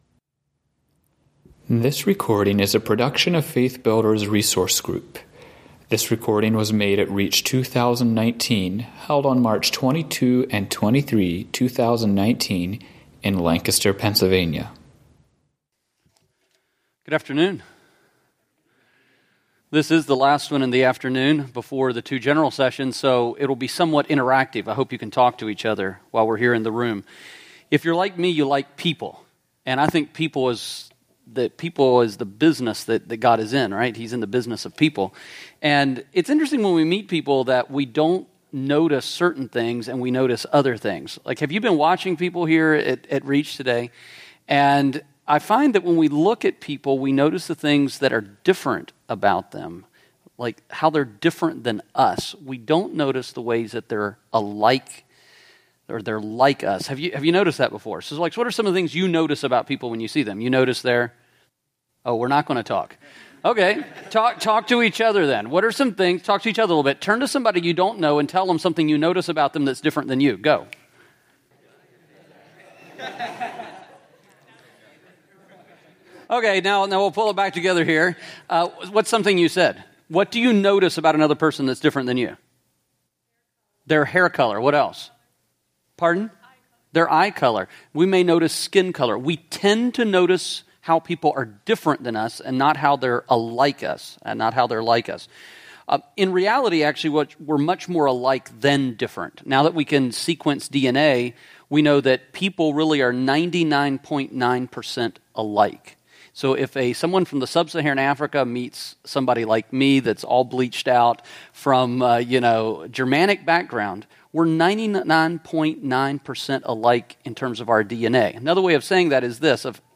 Home » Lectures » It’s Our Turn: Preparing for and Completing Our Part of the Mission